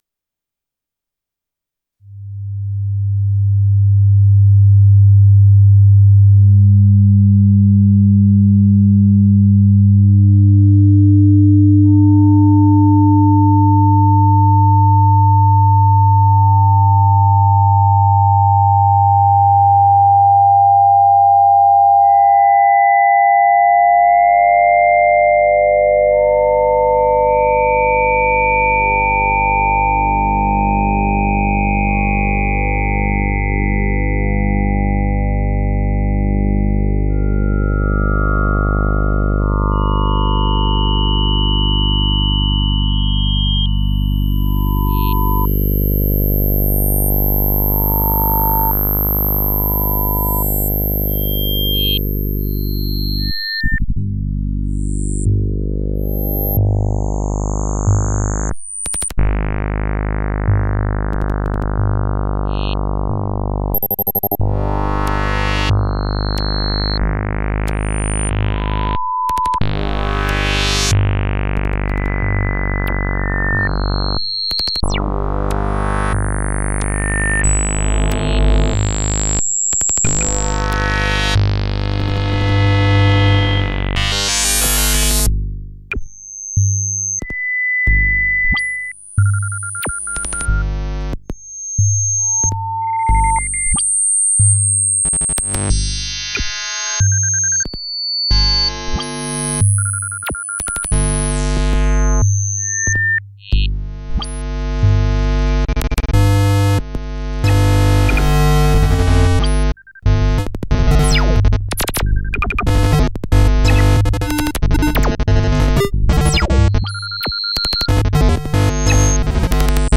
The stereo music of the latter one can actually be recorded from the video, and then replayed using your computer sound card. The generated stereo signals can be fed to any oscilloscope with XY mode to reproduce the cool Lissajous Patterns.